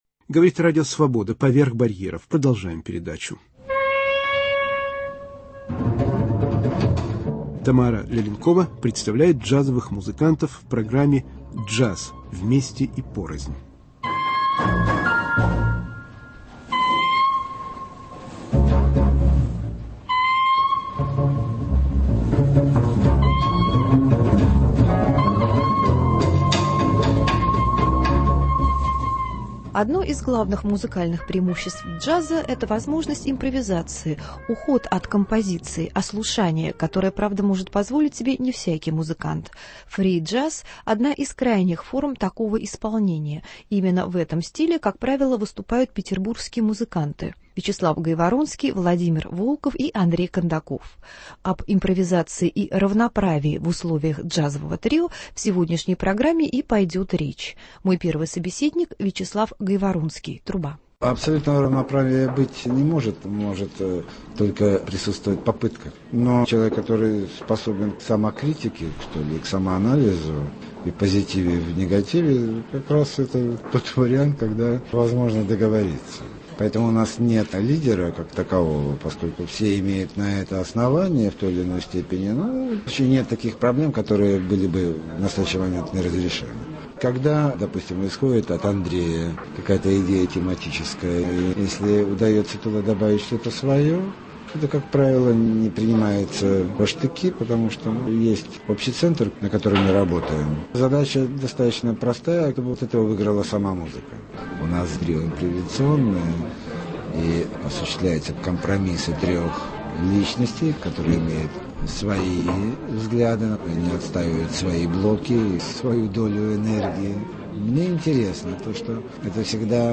Рассказвают и играют известные российские музыканты